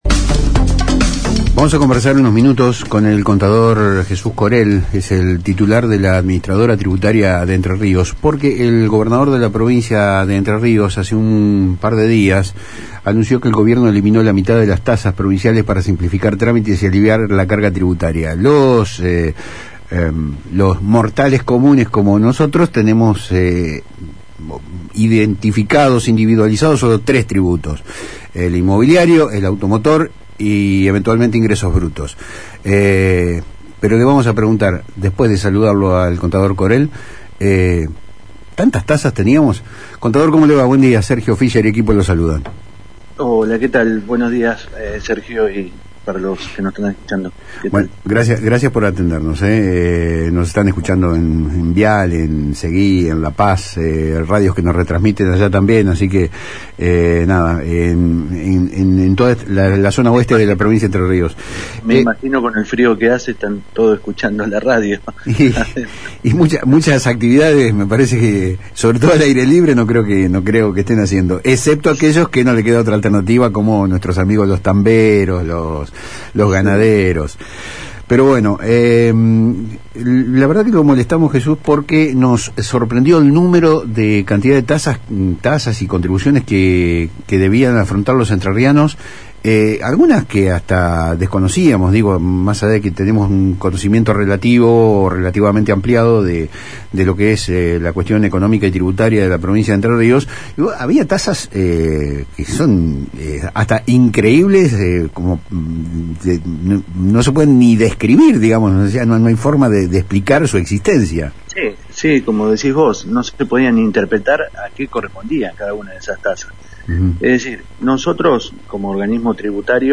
En dialogo con el programa Palabras Cruzadas de FM Litoral, el contador Jesús Korell, director ejecutivo de ATER, brindó detalles sobre la profunda transformación que está llevando adelante la administración tributaria en Entre Ríos.